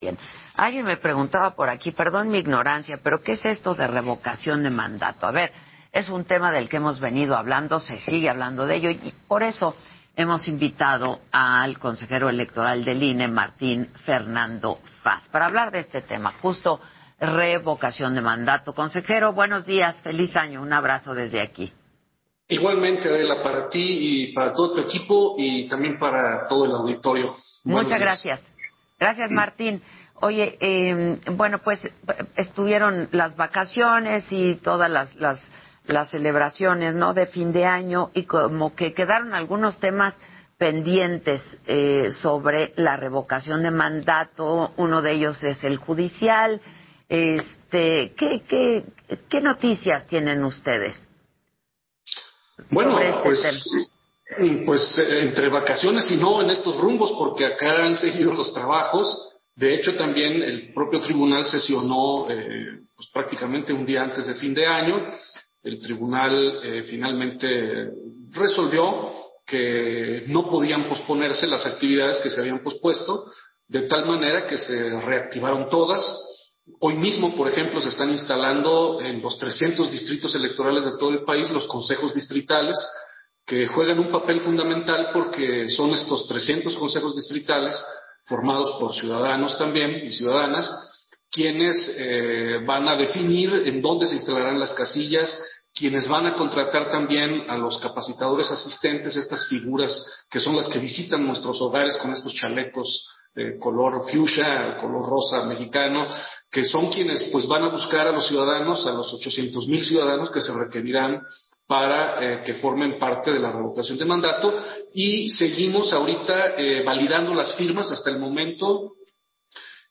El miércoles se definirá cuánto se pedirá a la Secretaría de Hacienda para la Revocación de Mandato; Martín Faz en entrevista con Adela Micha